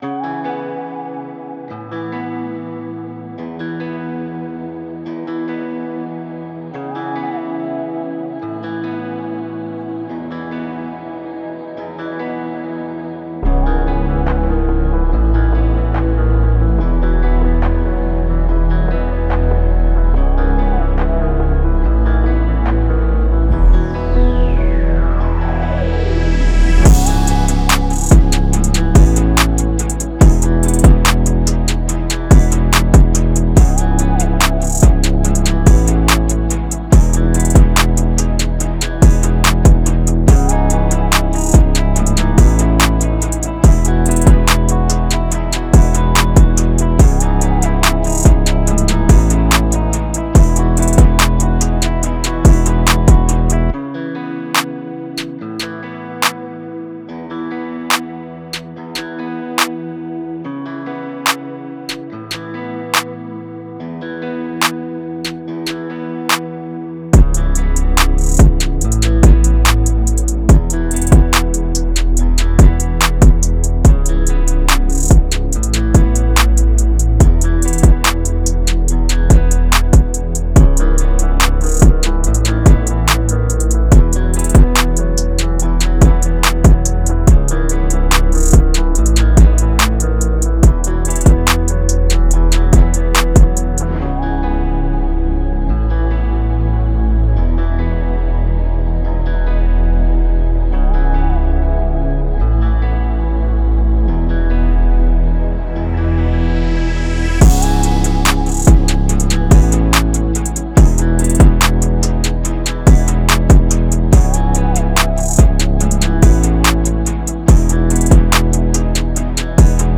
オリジナルKey：「E